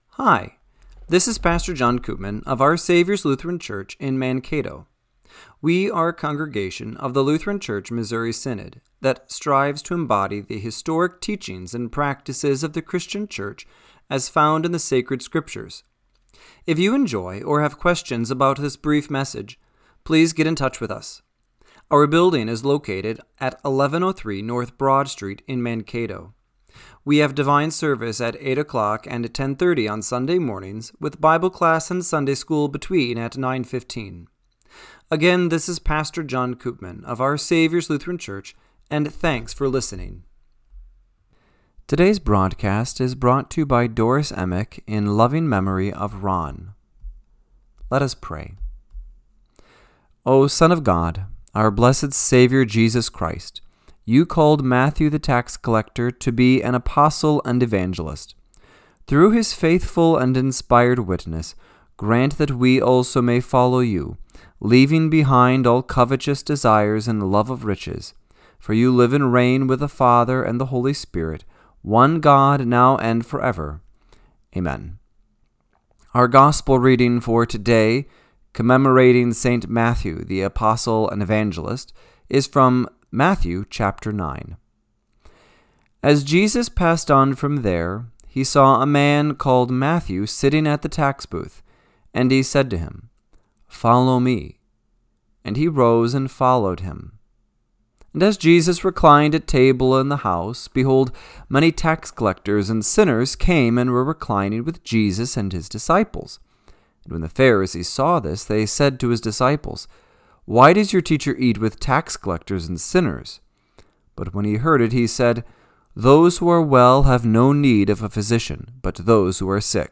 Radio-Matins-9-21-25.mp3